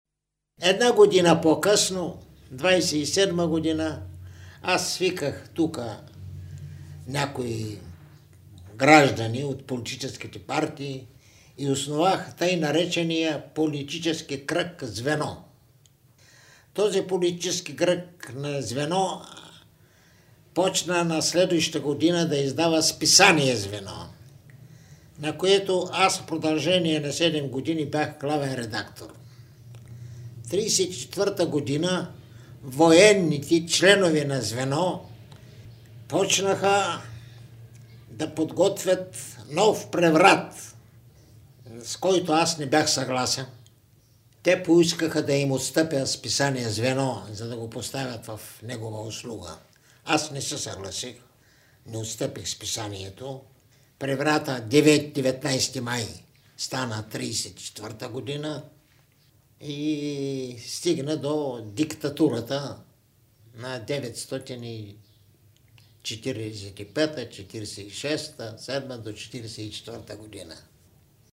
Предлагаме Ви няколко звукови документа, съхранени в Златния фонд на БНР, които представят аспекти от тези събития, както от преки свидетели и участници в тях, така и от дистанцията на времето и исторически поглед.